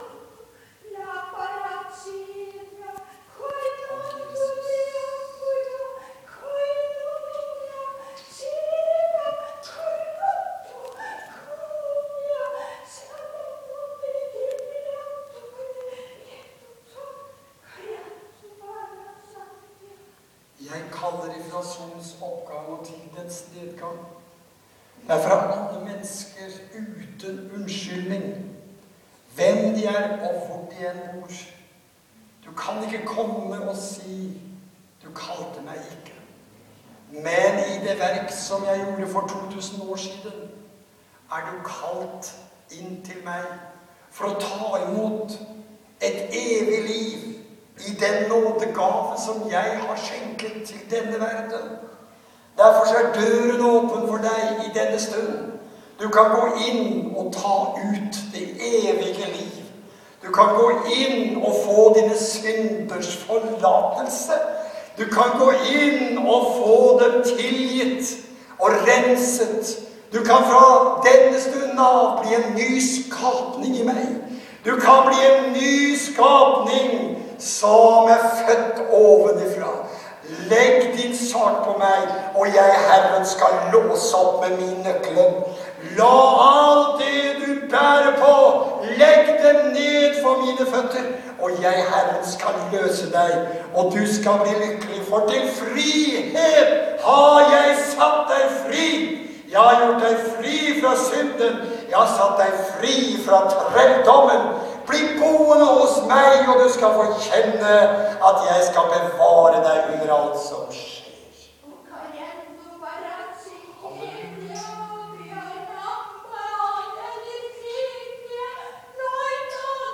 TALE.
TYDING AV TUNGETALE.